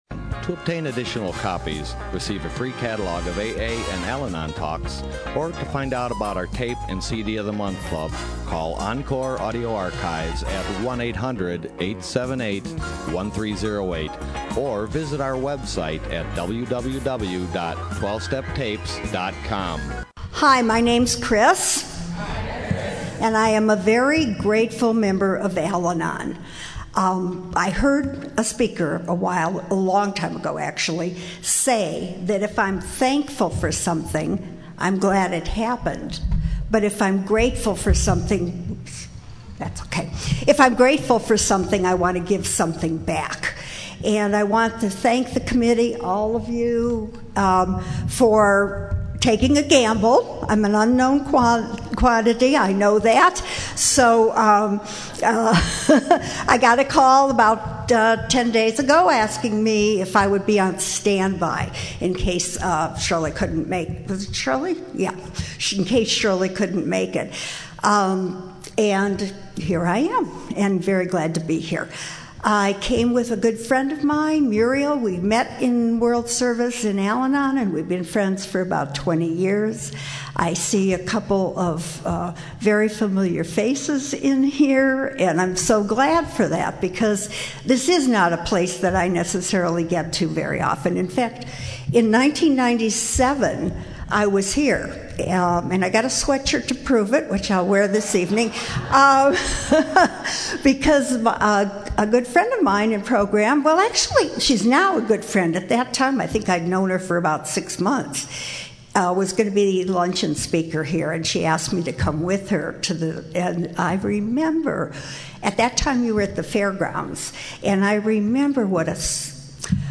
AFG Luncheon